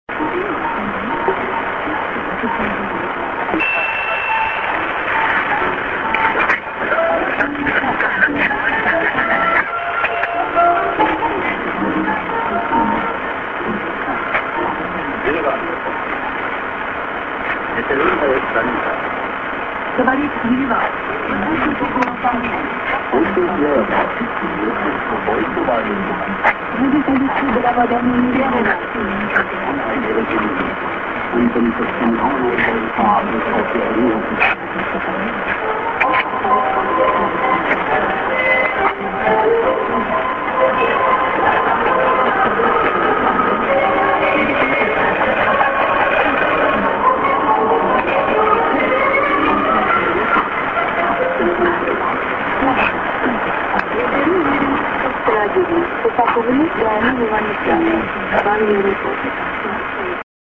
St. IS->ID(man+women)->